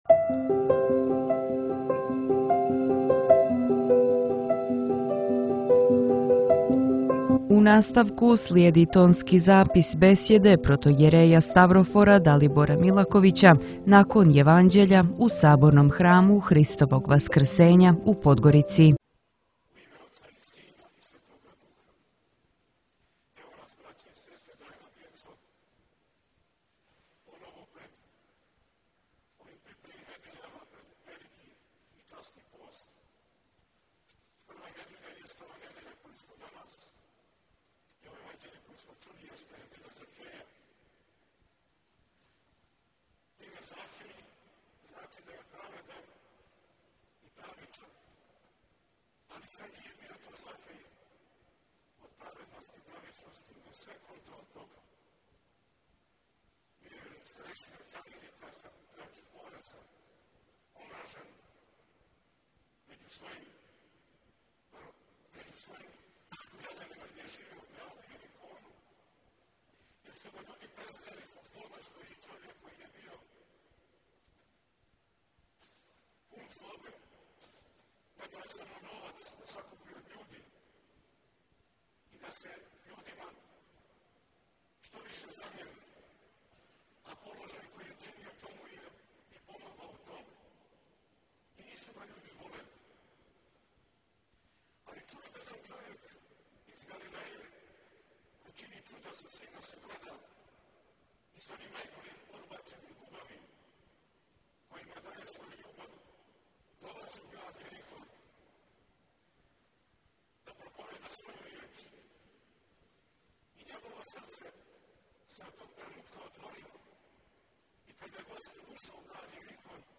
Бесједе